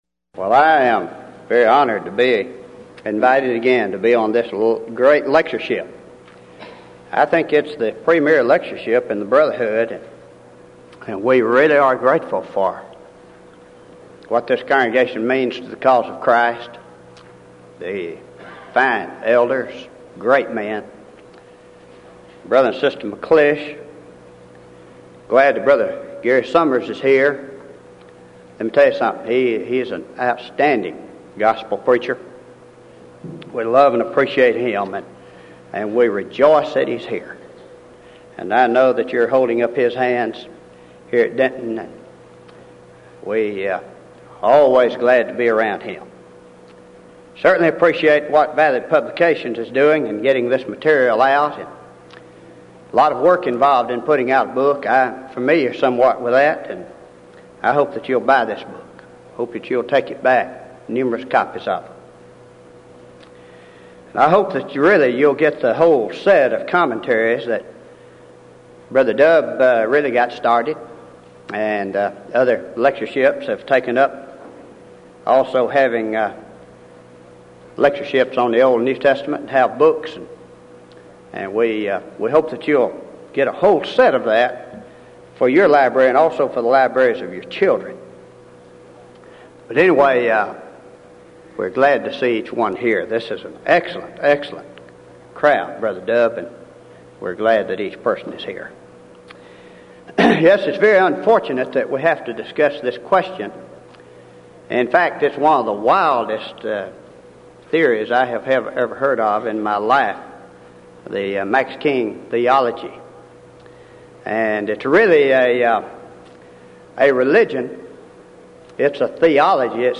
Title: DISCUSSION FORUM: Did The Destruction Of Jerusalem In A.D. 70 Constitute The Second Coming Of Christ And The End Of The World, And Have The Resurrection And The Judgment Already Occurred?